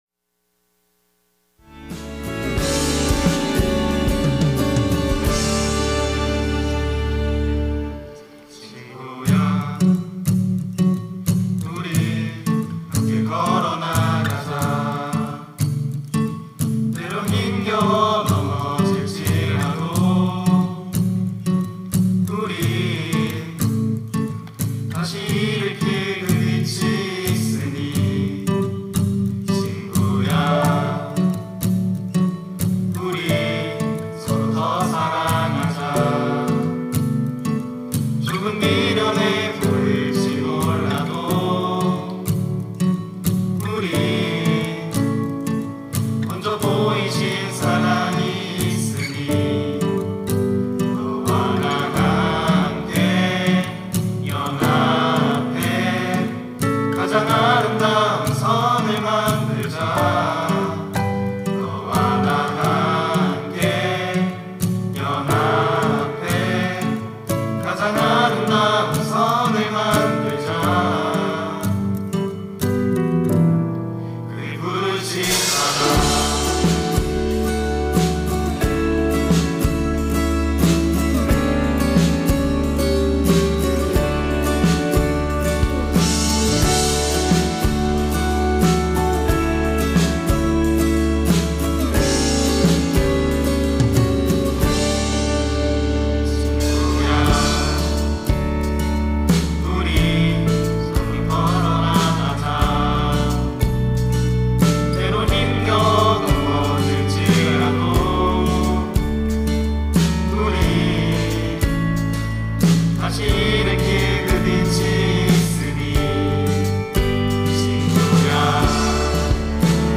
특송과 특주 - 친구야
청년부 30기